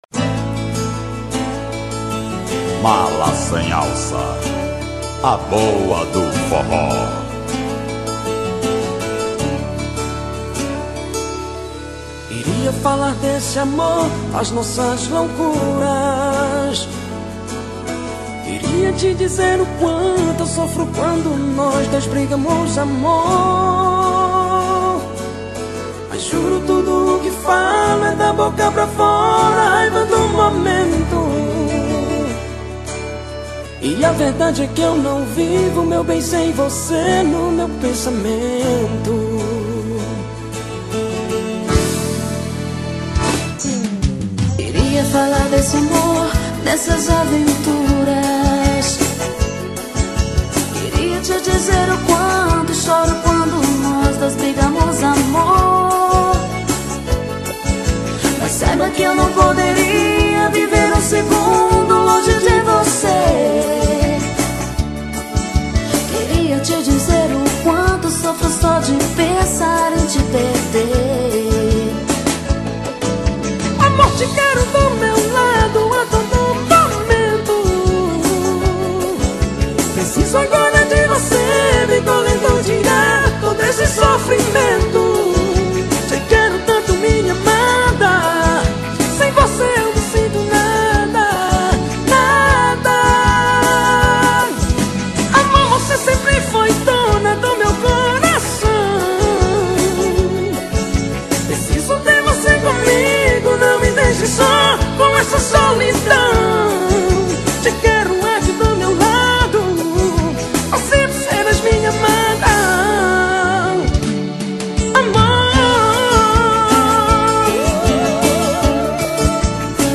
2024-12-19 08:52:59 Gênero: Forró Views